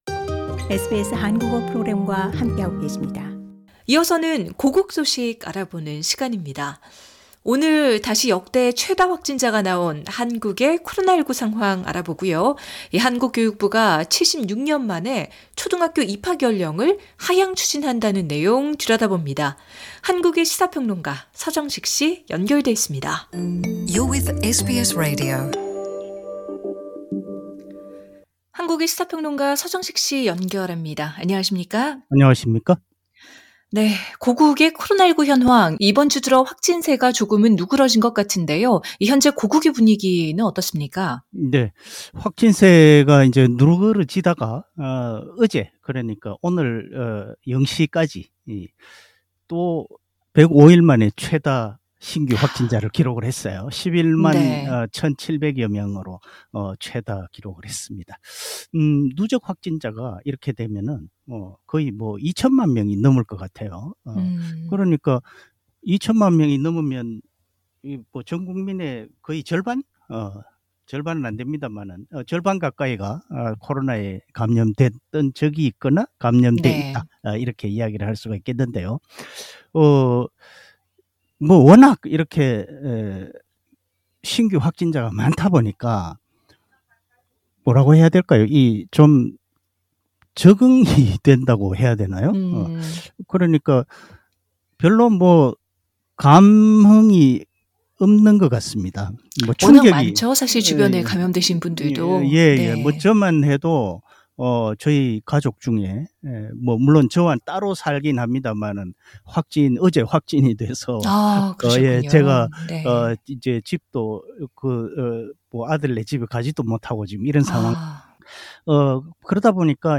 자세한 내용은 상단의 인터뷰에서 확인하실 수 있습니다.